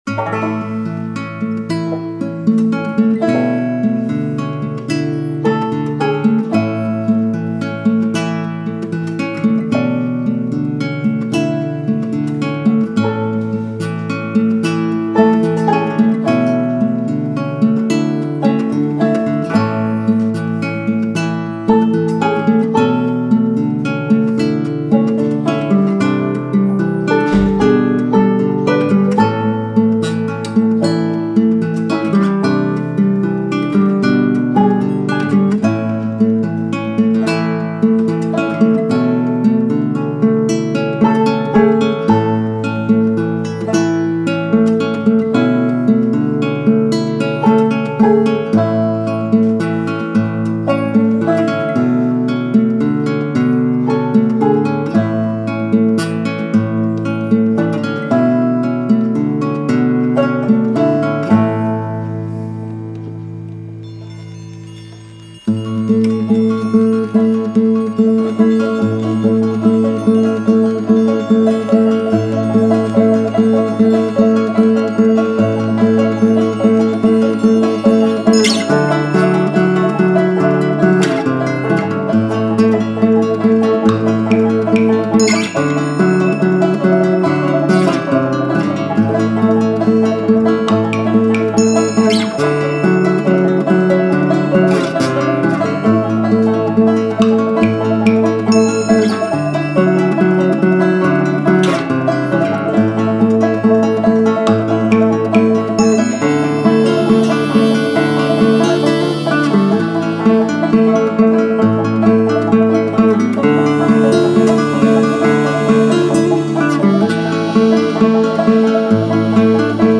guitar
banjo
bulbul tarang